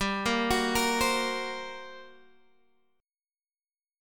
GmM7bb5 Chord